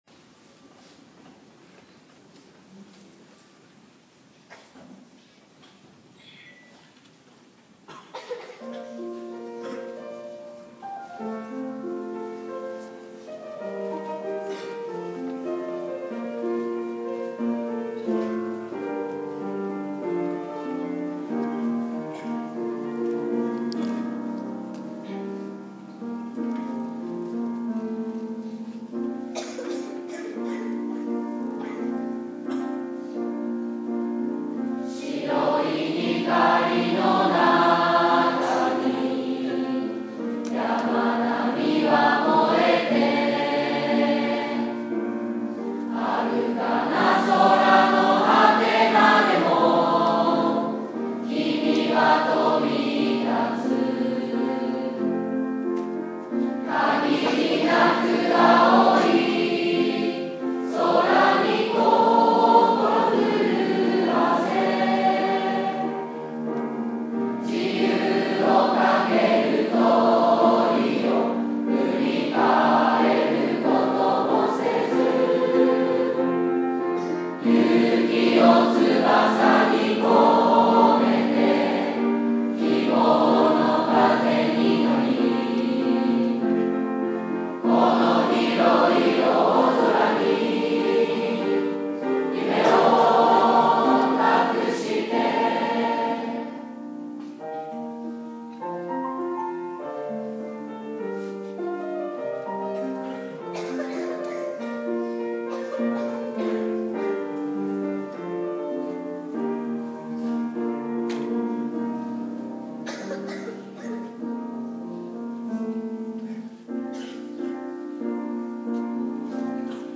３月１４日（金）　第51回卒業証書授与式
卒業生全員で美しいハーモニーを体育館に響かせました。